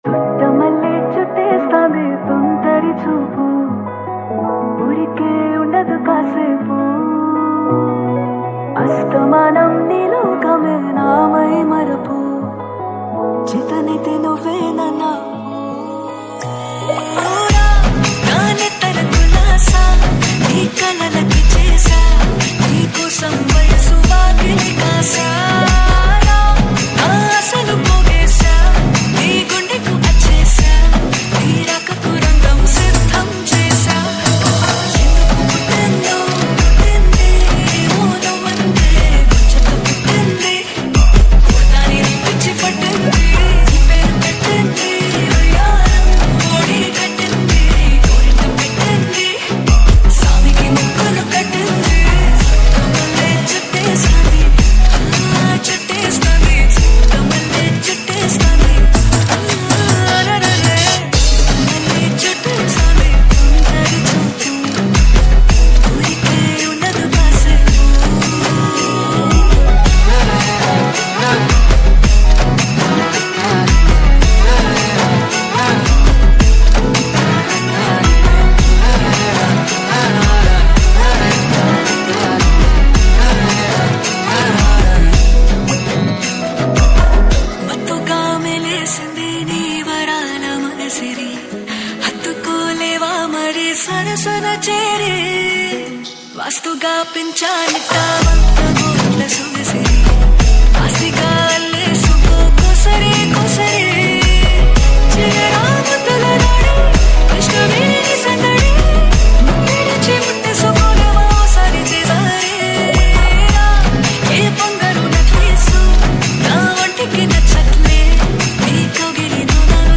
Telugu Love Dj Remix